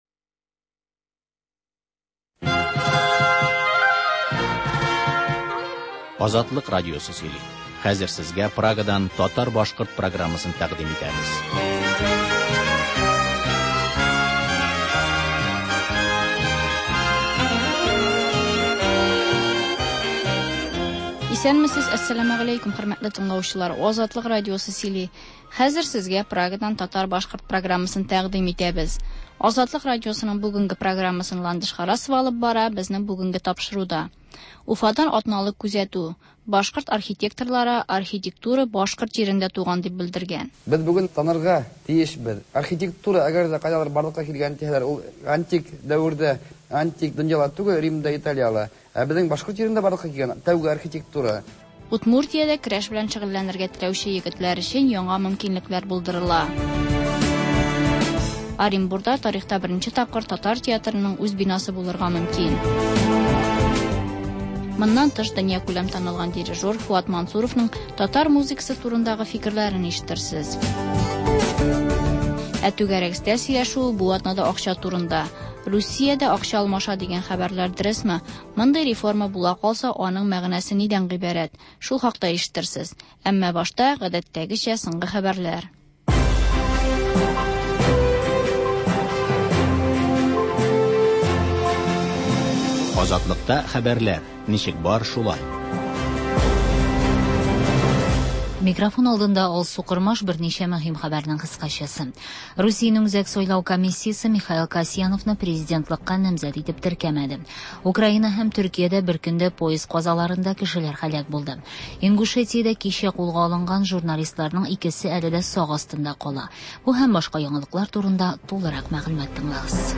Азатлык радиосы бар атнага күз сала - соңгы хәбәрләр - Башкортстаннан атналык күзәтү - түгәрәк өстәл артында сөйләшү